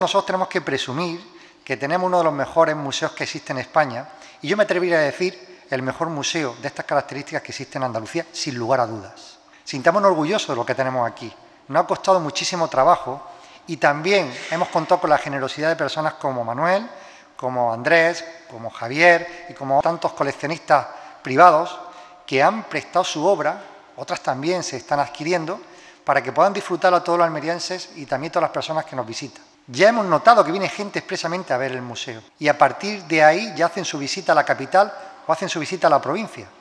La inauguración de esta muestra se ha llevado a cabo esta tarde en un acto que ha contado con una amplia representación de toda la sociedad almeriense